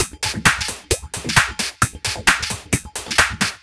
132 Skip N Groove No Bd.wav